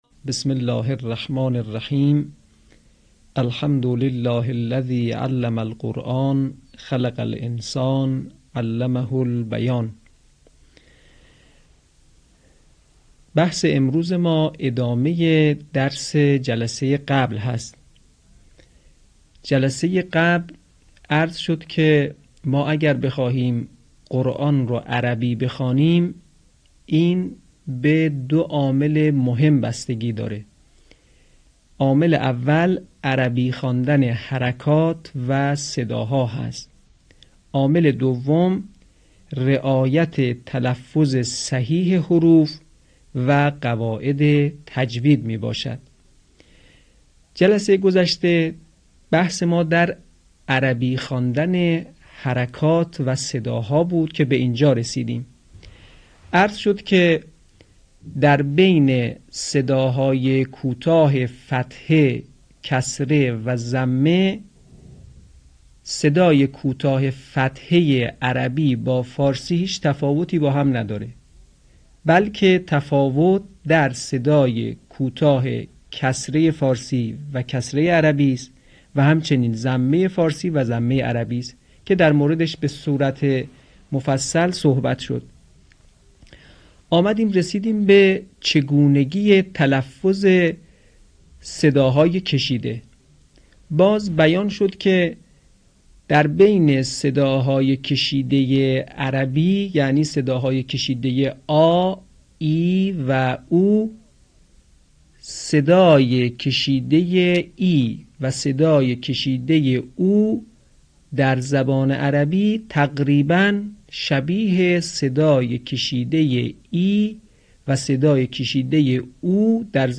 آموزش تجوید